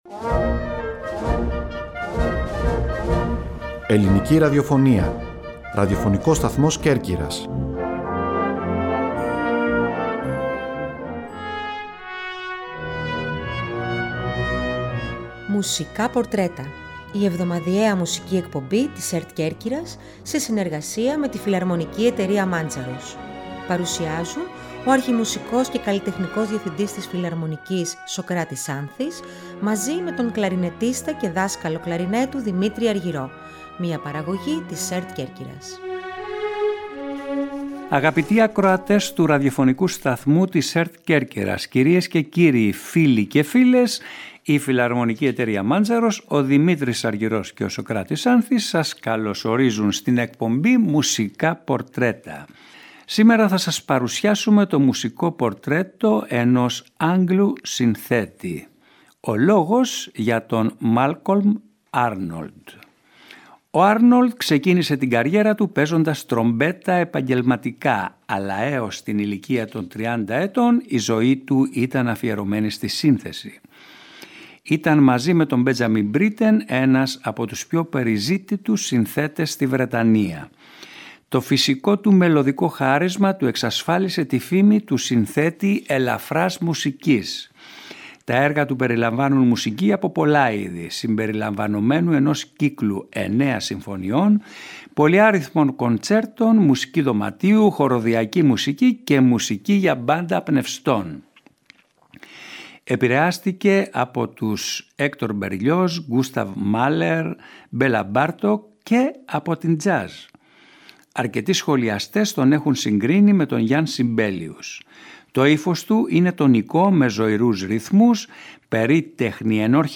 “Μουσικά Πορτρέτα” Εβδομαδιαία εκπομπή της ΕΡΤ Κέρκυρας σε συνεργασία με τη Φιλαρμονική Εταιρεία Μάντζαρος.